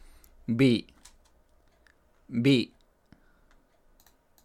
La consonante que aprenderemos a continuación suena igual que nuestra B de toda la vida.
Pronunciación 비